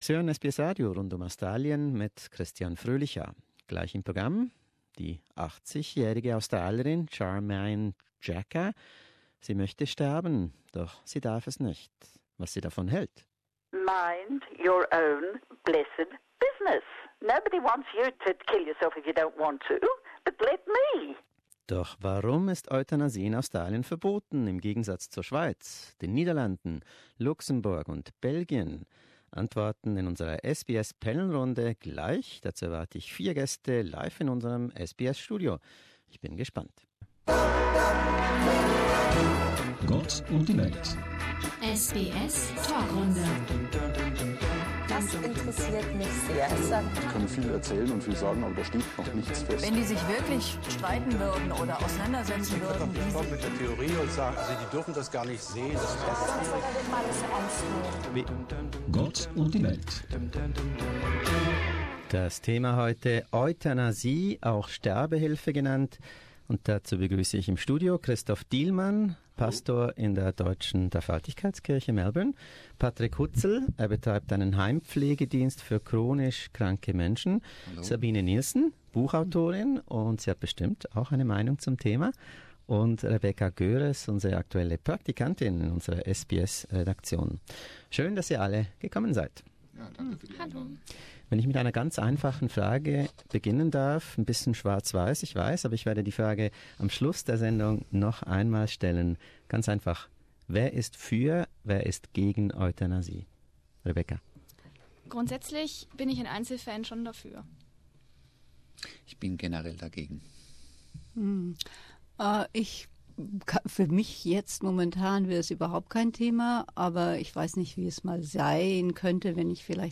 SBS panel discussion: The pros and cons of Euthanasia
But why is the practice illegal in most other countries, including Australia? We invited a pastor, an author, a carer and a student to our SBS studio, for a candid discussion.